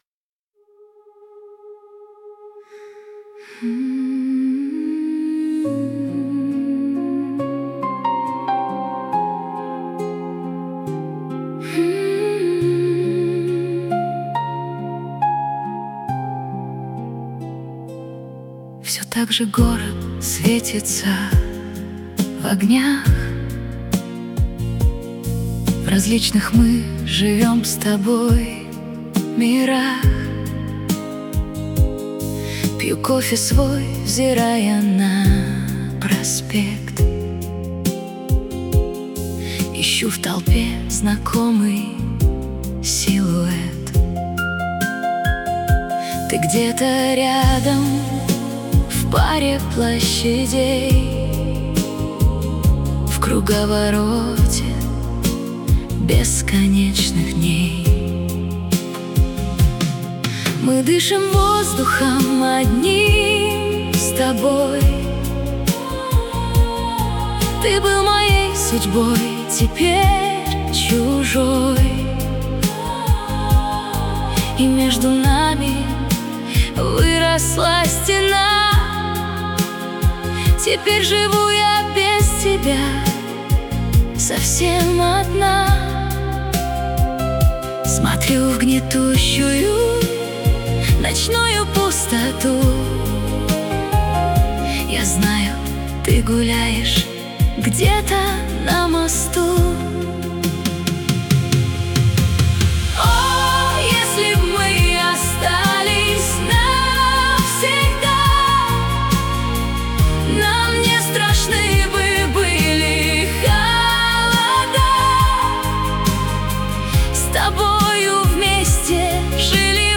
mp3,6076k] AI Generated